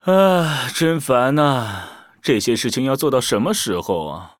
文件 文件历史 文件用途 全域文件用途 Kagon_fw_03.ogg （Ogg Vorbis声音文件，长度4.4秒，103 kbps，文件大小：55 KB） 源地址:地下城与勇士游戏语音 文件历史 点击某个日期/时间查看对应时刻的文件。 日期/时间 缩略图 大小 用户 备注 当前 2018年5月13日 (日) 02:15 4.4秒 （55 KB） 地下城与勇士  （ 留言 | 贡献 ） 分类:卡坤 分类:地下城与勇士 源地址:地下城与勇士游戏语音 您不可以覆盖此文件。